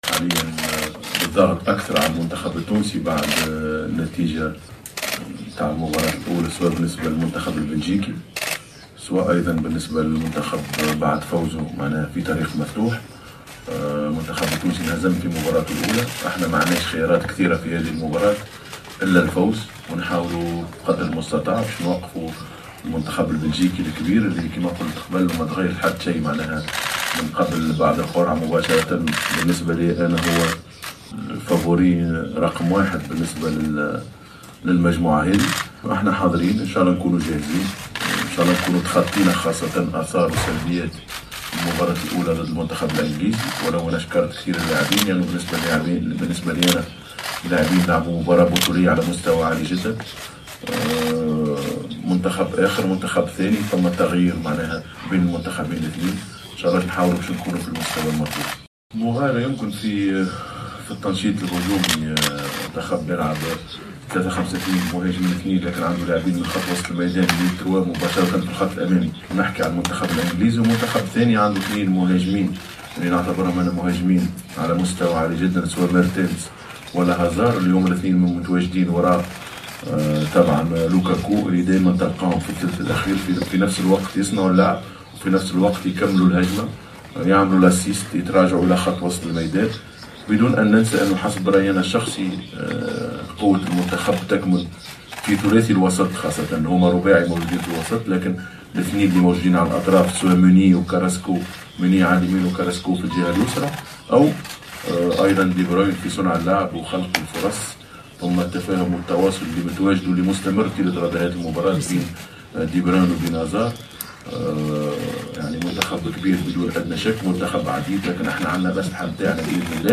اكد مدرب المنتخب الوطني نبيل معلول خلال الندوة الصحفية التي عقدها اليوم الجمعة صحبة المهاجم وهبي الخزري ان مباراة بلجيكا المبرمجة غدا السبت 23 جوان 2018 في اطار الجولة الثانية من منافسات المجموعة السابعة ستكون مصيرية .